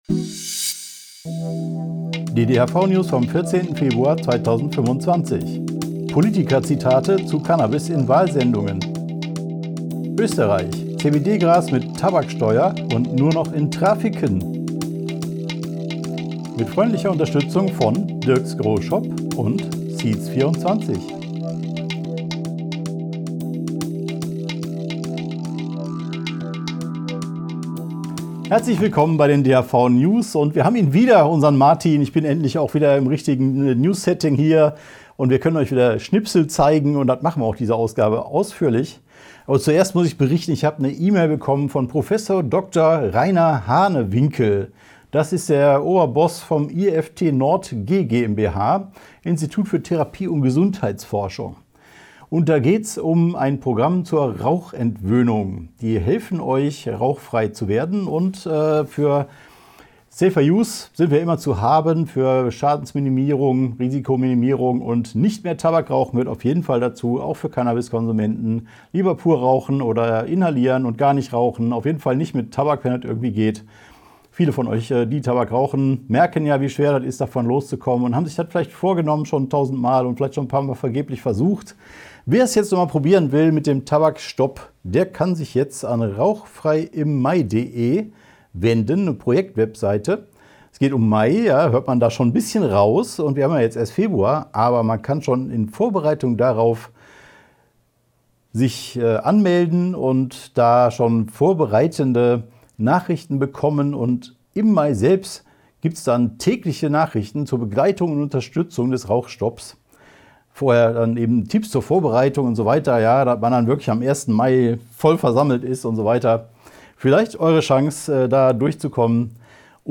DHV-News # 456 Die Hanfverband-Videonews vom 14.02.2025 Die Tonspur der Sendung steht als Audio-Podcast am Ende dieser Nachricht zum downloaden oder direkt hören zur Verfügung.